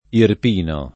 irp&no] etn. — anche nelle denominaz. di comuni della provincia di Avellino: Ariano Irpino, Bagnoli I., Cassano I., Melito I., Montecalvo I., Monteforte I., Petruro I., Savignano I., Altavilla Irpina, Capriglia Irpina, Salza Irpina, Volturara Irpina; e in passato, Morra Irpino